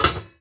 metal_lid_movement_impact_13.wav